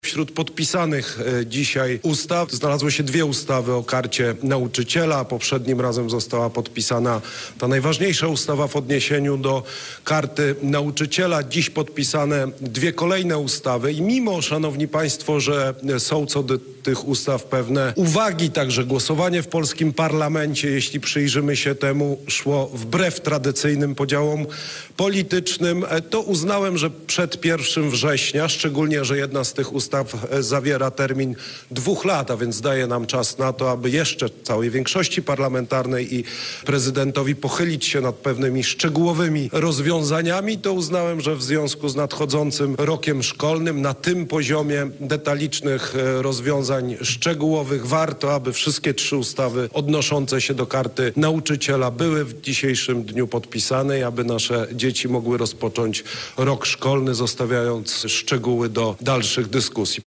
Tłumaczy Prezydent RP Karol Nawrocki.